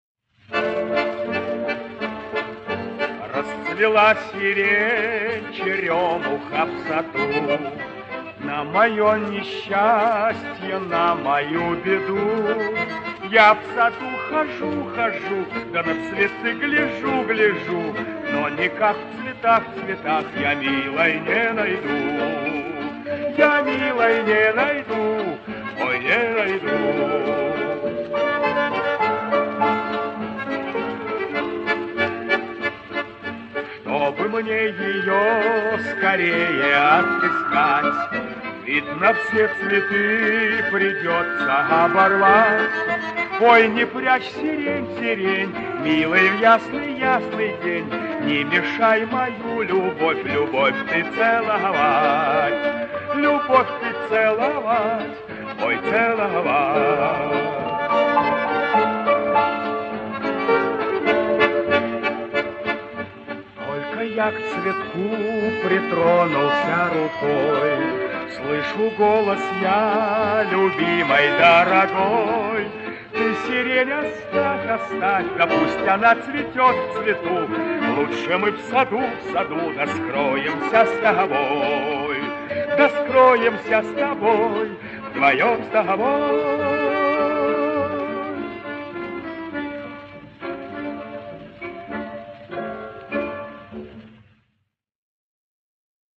Добрая военная комедия и добрые лиричные песни.
Инстр. квартет